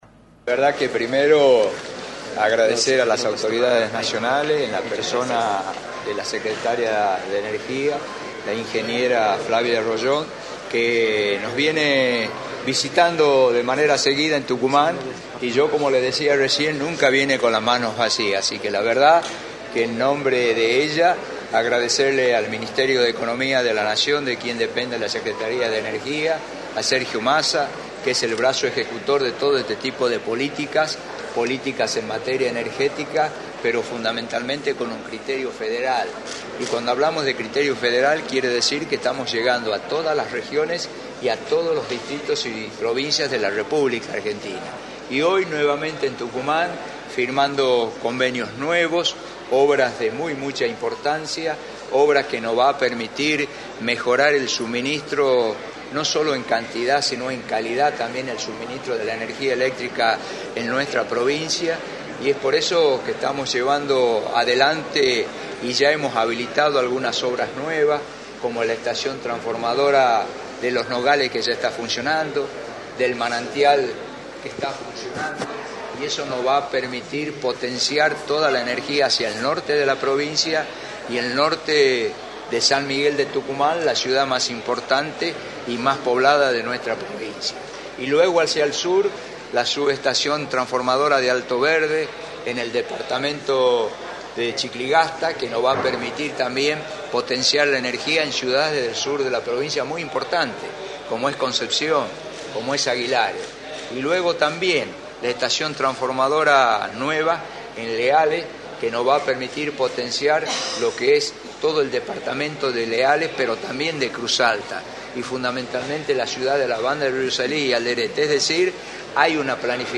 Osvaldo Jaldo, Vicegobernador y Gobernador electo, informó en Radio del Plata Tucumán, por la 93.9, los alcances de los convenios firmados con la Secretaría de Energía y, por otro lado, remarcó su postura con respecto a la polémica con los Intendentes.